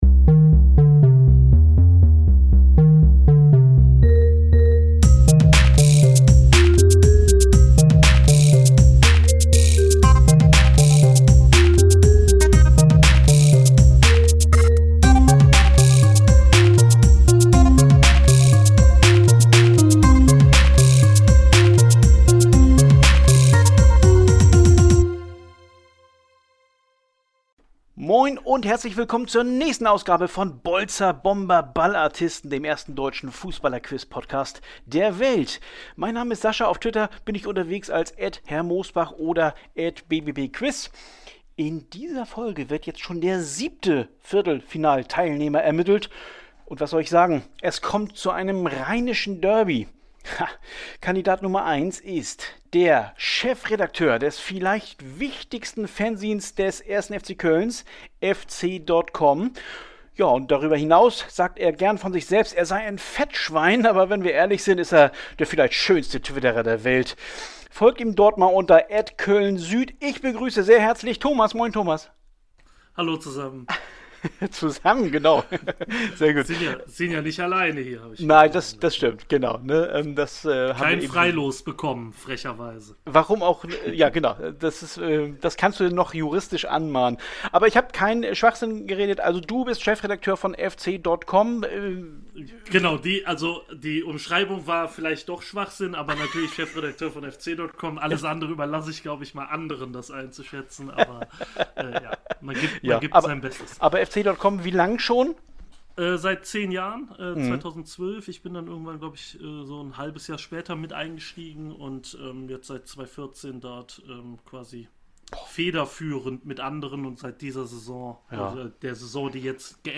Eigentlich sollte die Szene, die sich kurz vor Minute 4 abspielt, herausgeschnitten werden.